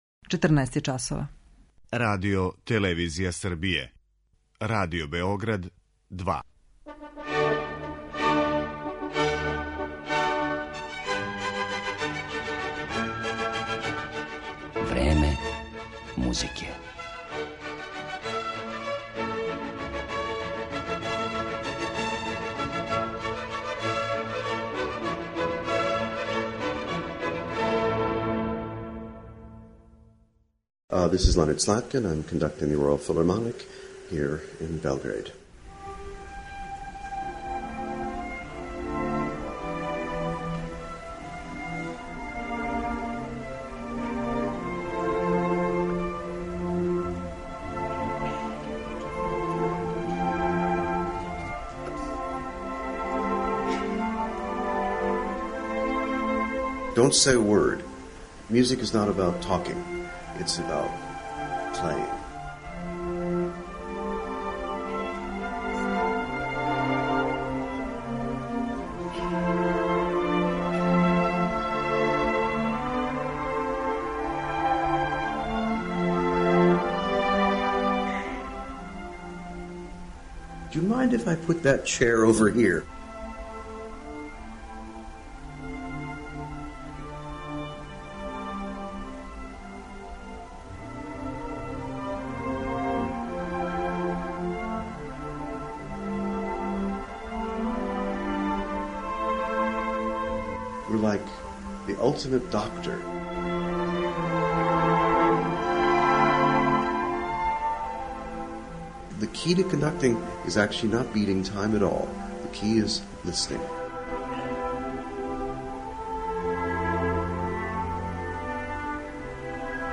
Том приликом снимљен је и интервју са овим великим америчким музичарем који ћете моћи да слушате у емисији.